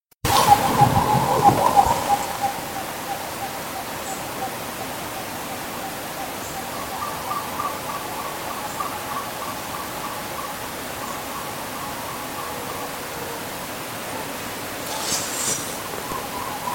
Spot-winged Wood Quail (Odontophorus capueira)
Province / Department: Misiones
Location or protected area: Parque Provincial Salto Encantado
Condition: Wild
Certainty: Recorded vocal